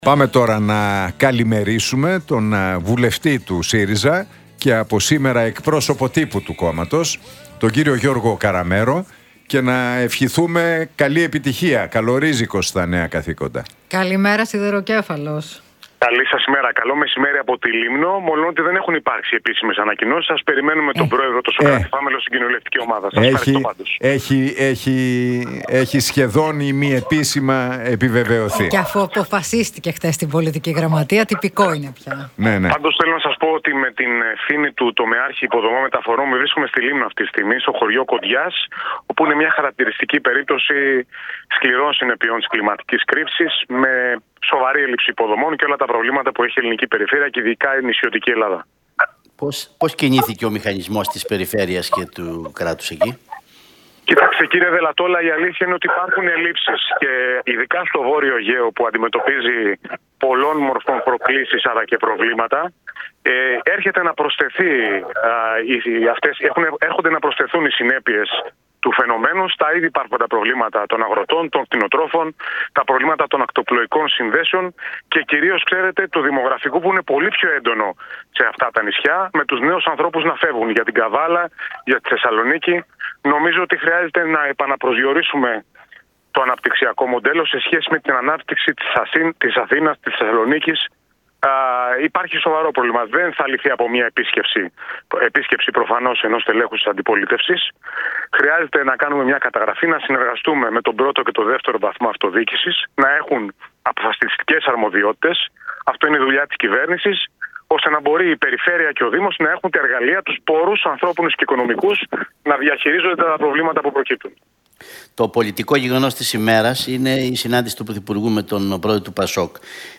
Στην Λήμνο μετά την κακοκαιρία «Bora» βρίσκεται ο Γιώργος Καραμέρος και από εκεί μίλησε για τα προβλήματα του νησιού αλλά και τις πολιτικές εξελίξεις στον